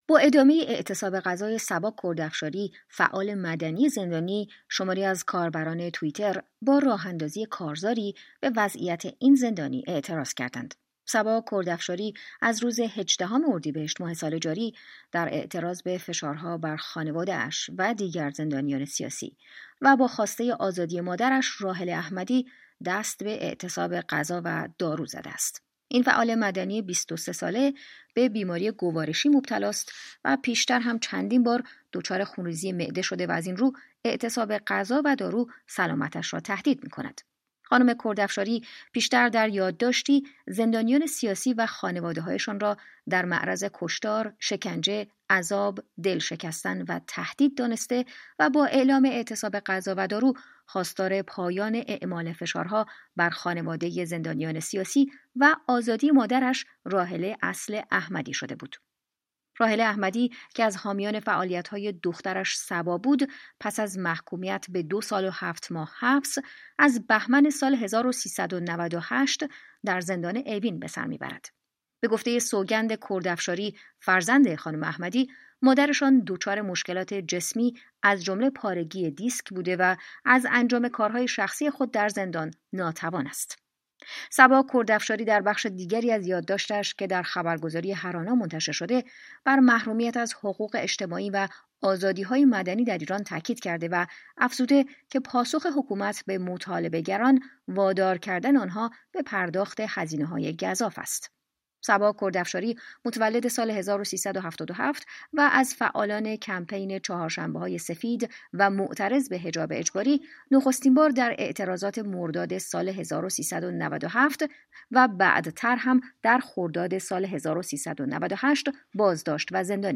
جزییات بیشتر در گزارشی